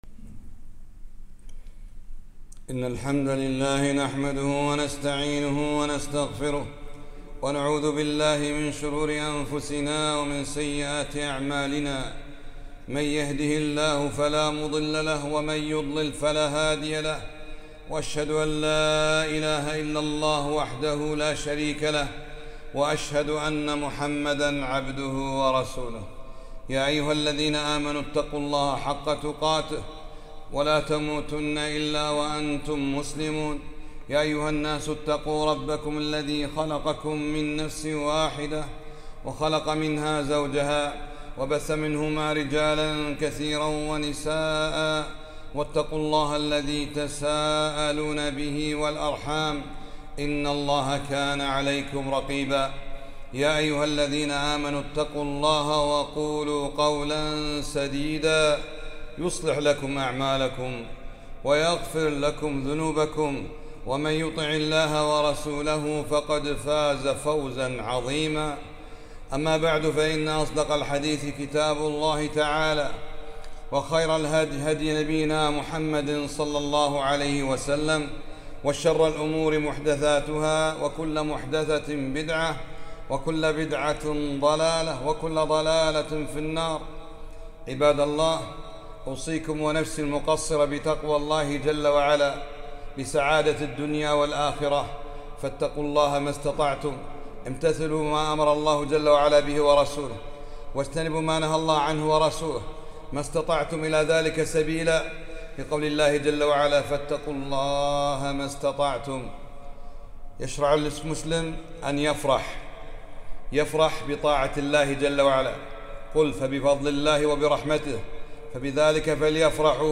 خطبة - محبة النبي ﷺ بالاتباع لا بإقامة المولد